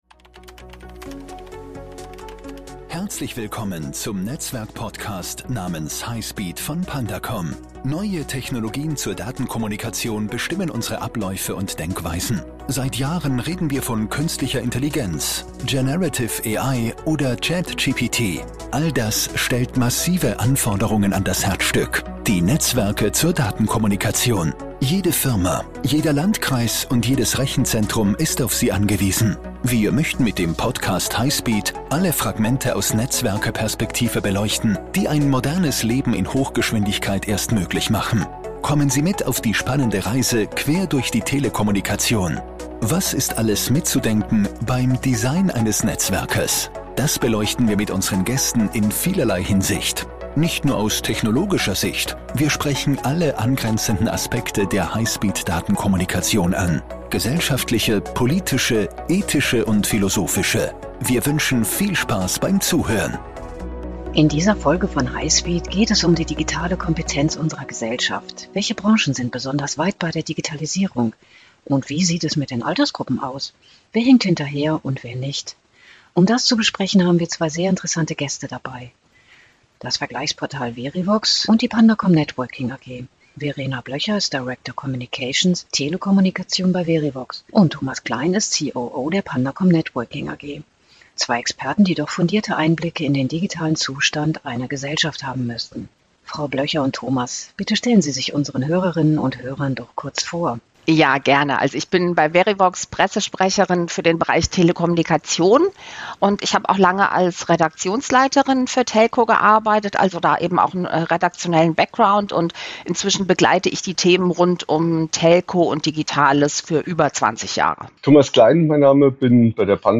Denn dann setzen sich beide Gesprächspartner mit der Frage auseinander, inwiefern wir Digitalisierung und allen ihren Konsequenzen blind vertrauen sollten.